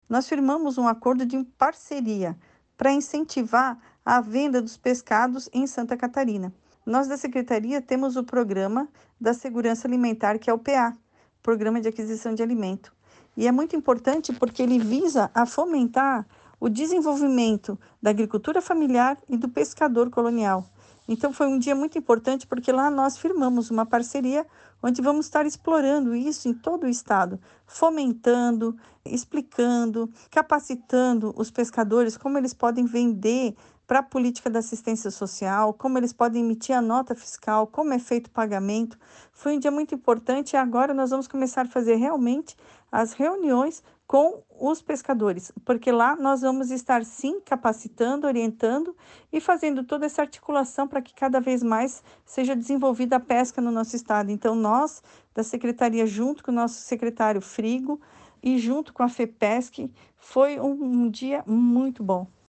O intuito é cadastrar o maior número de pescadores do estado e fomentar o setor como explica a secretária da SAS, Maria Helena Zimmermann: